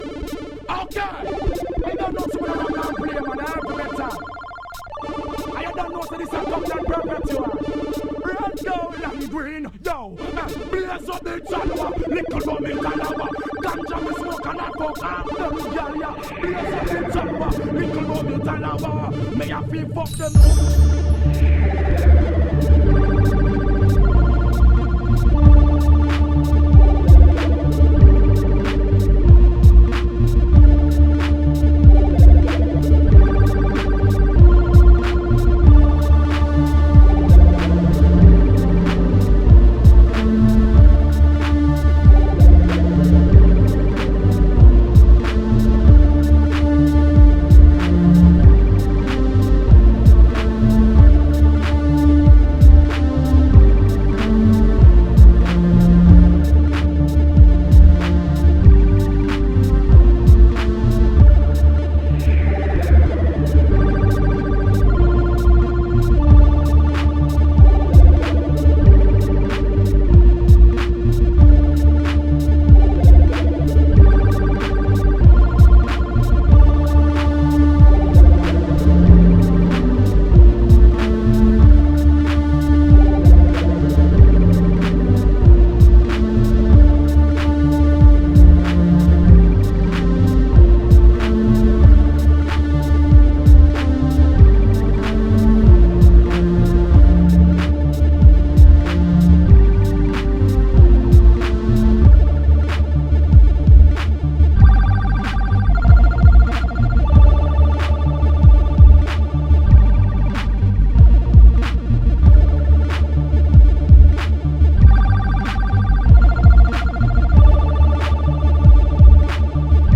AKAI MPC XL (Rhode Instrumental Test